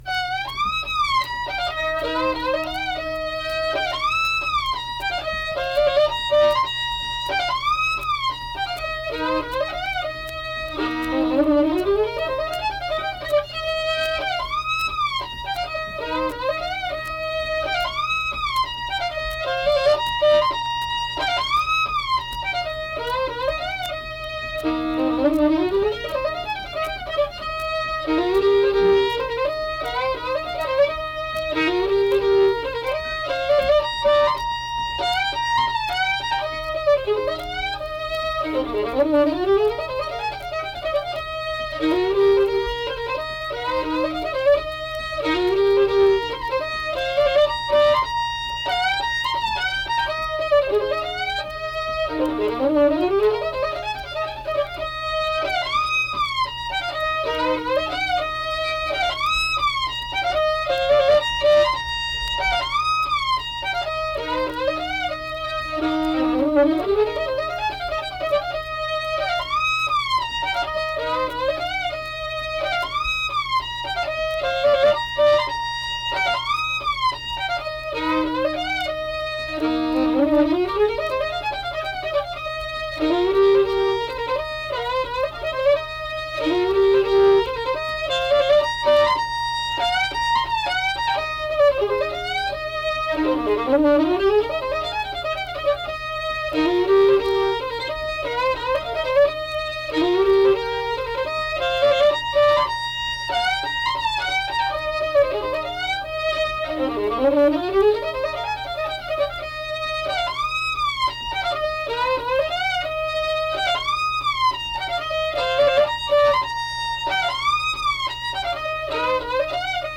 Unaccompanied fiddle music and accompanied (guitar) vocal music
Blues, Instrumental Music
Fiddle